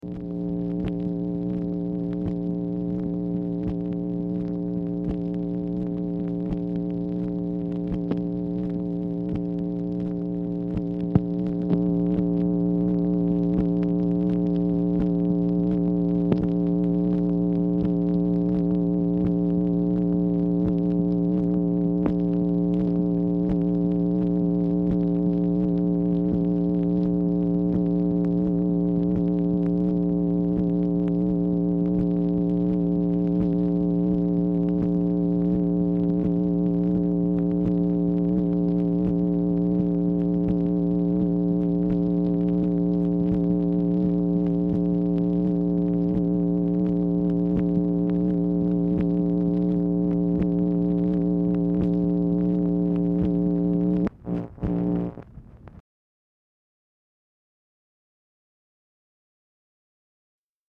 Telephone conversation # 9008, sound recording, MACHINE NOISE, 10/1/1965, time unknown
MACHINE NOISE
Oval Office or unknown location
Telephone conversation
Dictation belt